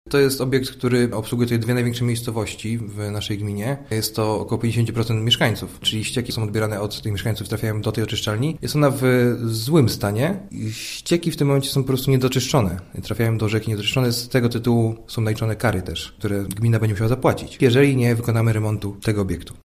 – Aby jednak zapewnić prawidłową gospodarkę ściekową w gminie, musimy zmodernizować oczyszczalnię w Dychowie – mówi Wojciech Wąchała, wójt gminy Bobrowice.